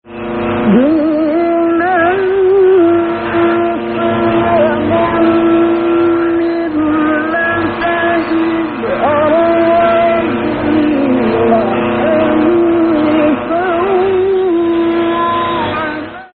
Вещалка номер 3 11783кГц